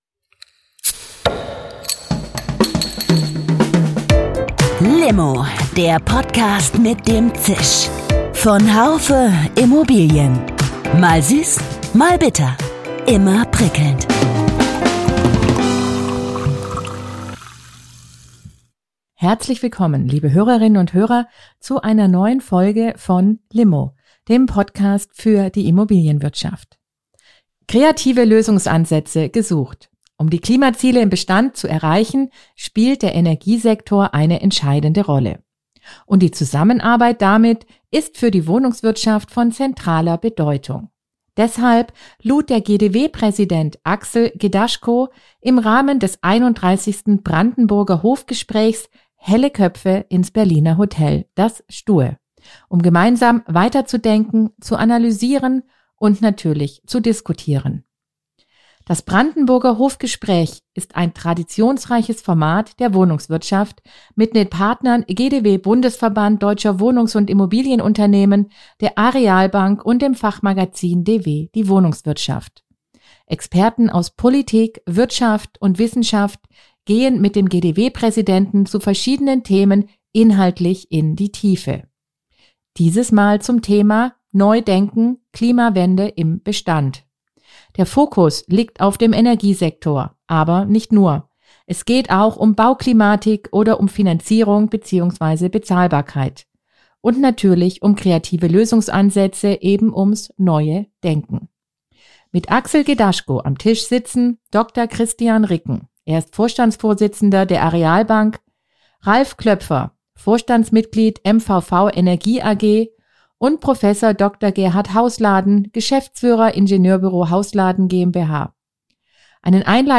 Experten aus Politik, Wirtschaft und Wissenschaft gehen mit dem GdW-Präsidenten zu verschiedenen Themen inhaltlich in die Tiefe.